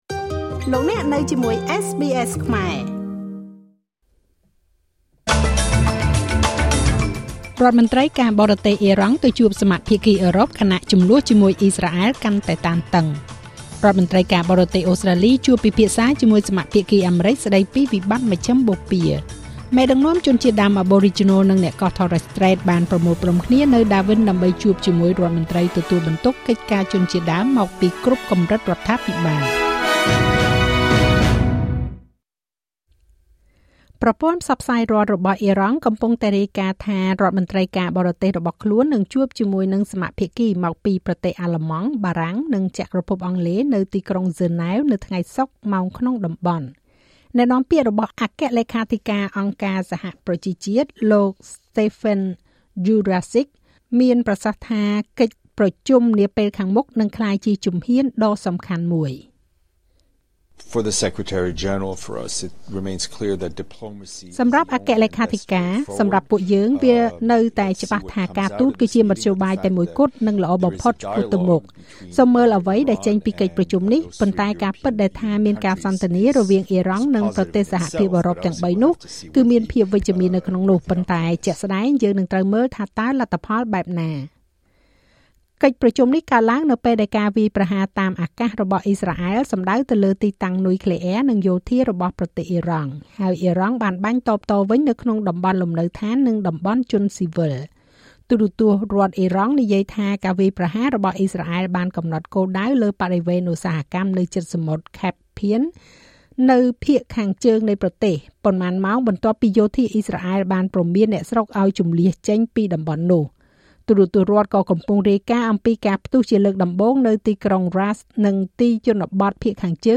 នាទីព័ត៌មានរបស់SBSខ្មែរ សម្រាប់ថ្ងៃសុក្រ ទី២០ ខែមិថុនា ឆ្នាំ២០២៥